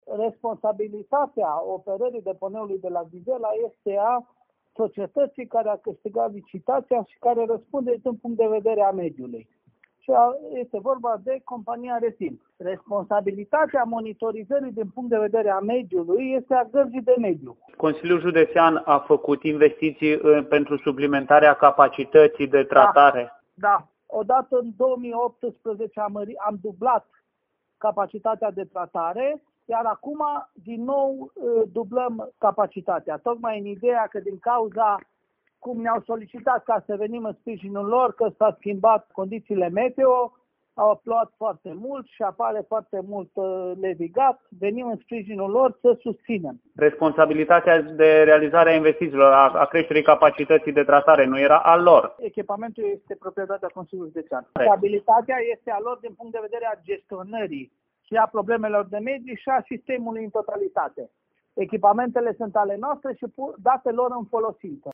Președintele Consiliului Județean Timiș, Călin Dobra, susține, la rândul său, că deși CJ Timiș s-a implicat pentru creșterea capacității de tratare a levigat, întreaga responsabilitate pentru gestionarea deponeului îi revine Retimului: